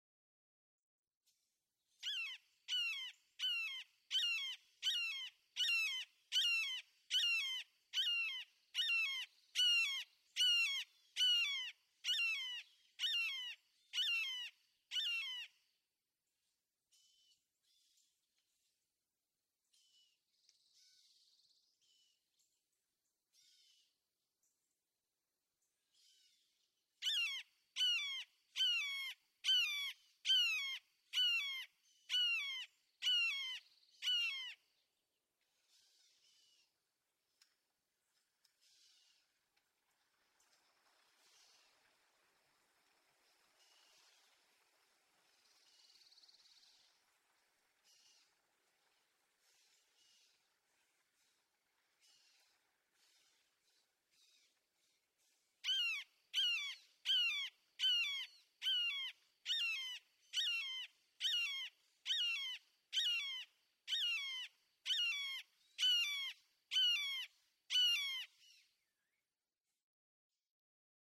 Red-shouldered hawk
♫37. Three calling bursts from an adult.
Picayune Strand State Forest, Naples, Florida.
037_Red-shouldered_Hawk.mp3